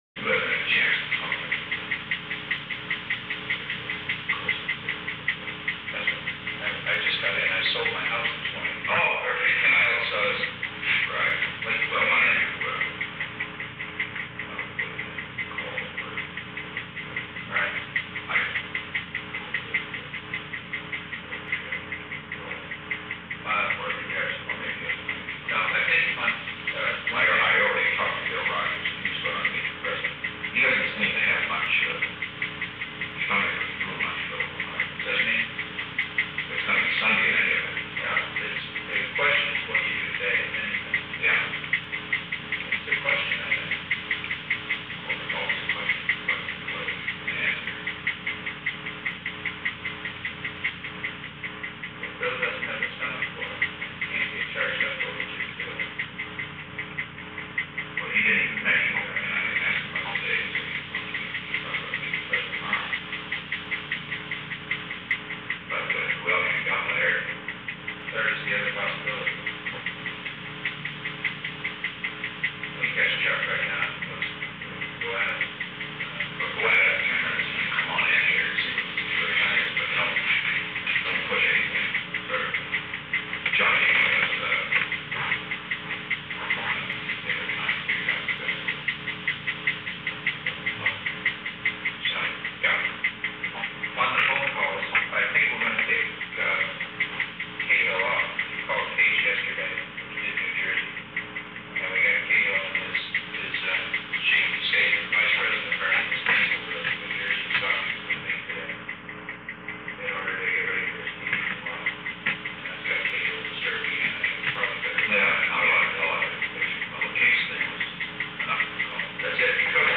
Recording Device: Old Executive Office Building
On November 4, 1972, President Richard M. Nixon and H. R. ("Bob") Haldeman met in the President's office in the Old Executive Office Building from 9:28 am to 9:33 am. The Old Executive Office Building taping system captured this recording, which is known as Conversation 389-009 of the White House Tapes.